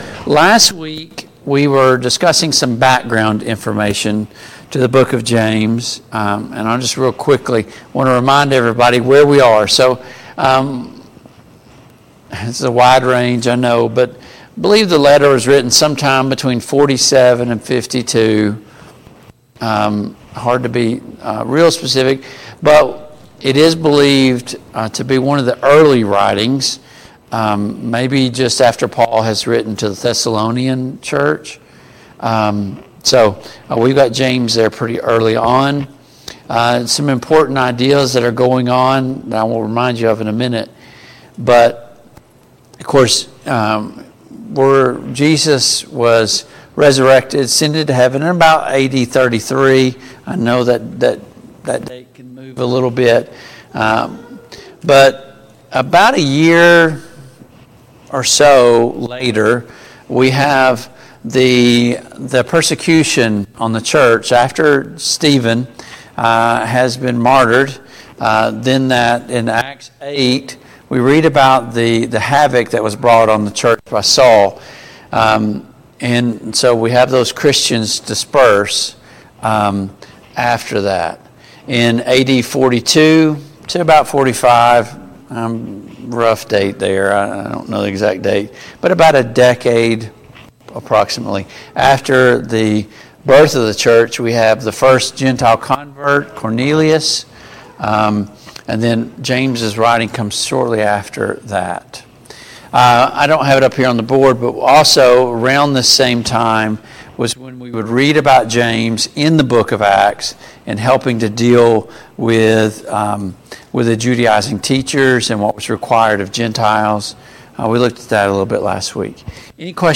Study of James and 1&2 Peter Passage: James 1:1-5 Service Type: Family Bible Hour « Are you giving God your best?